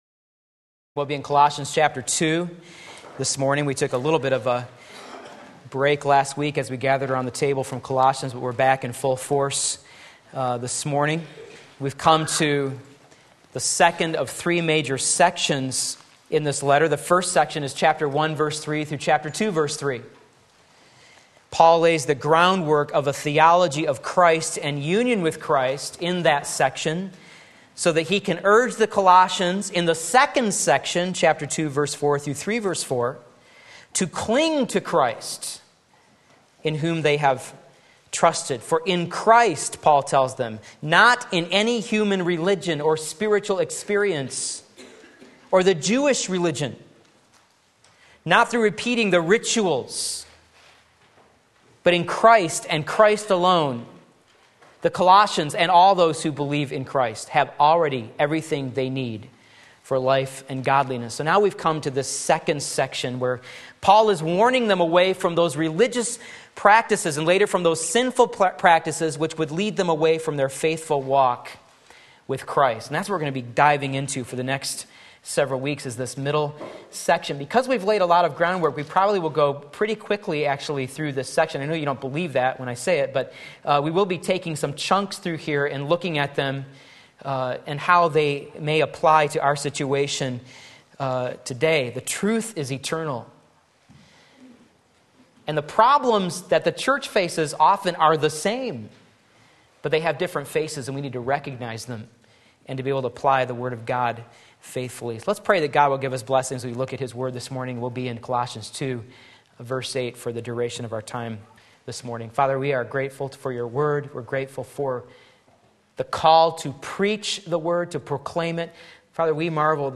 Sermon Link
Against Bad Philosophy Colossians 2:8 Sunday Morning Service